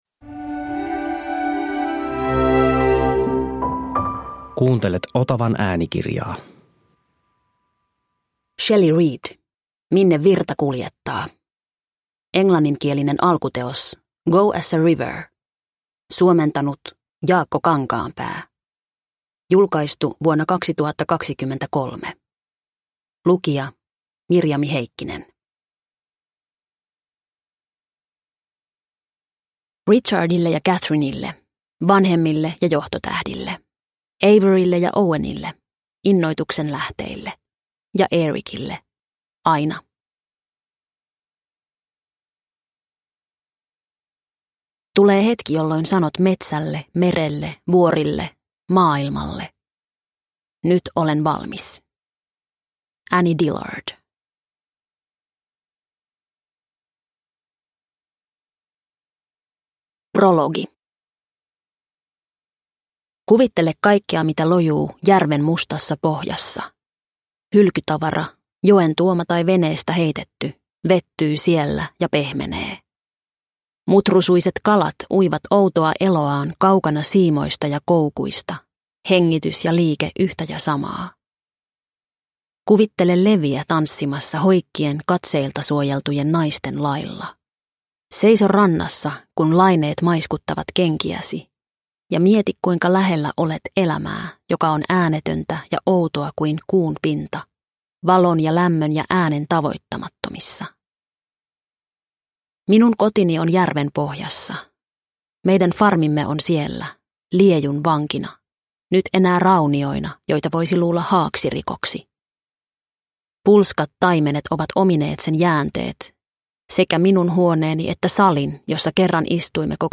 Minne virta kuljettaa – Ljudbok – Laddas ner